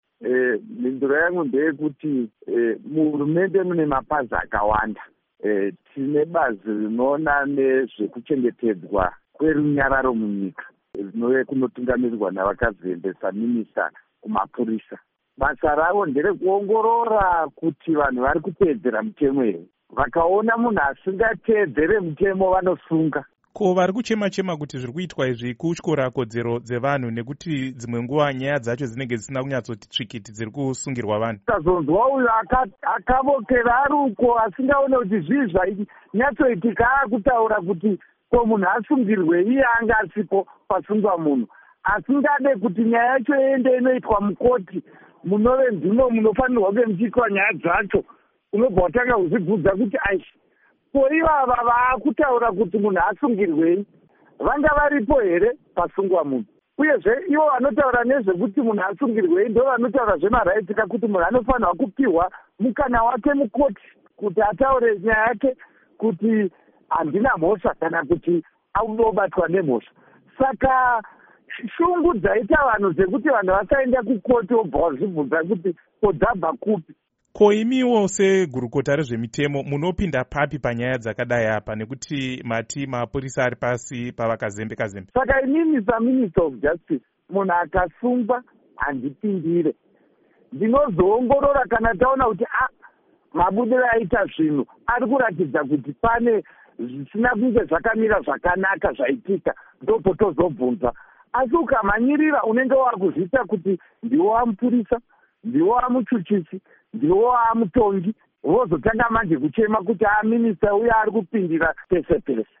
Hurukuro naVaZiyambi Ziyambi